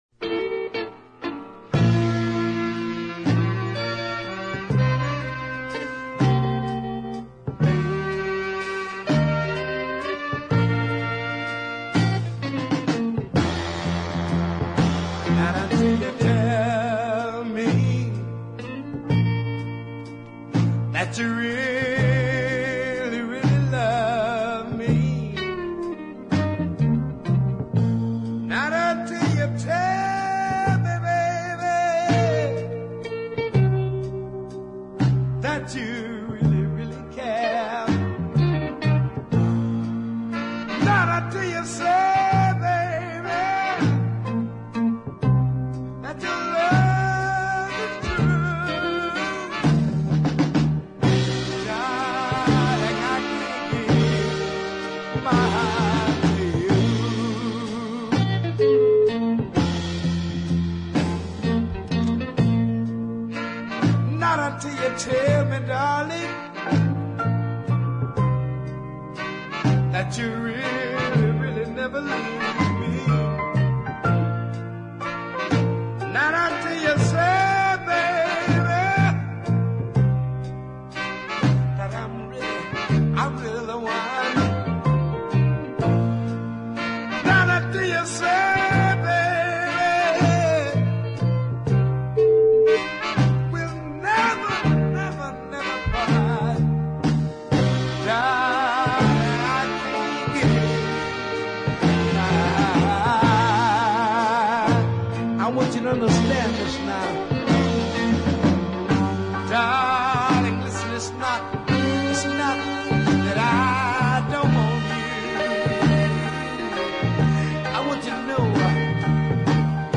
attractive light baritone voice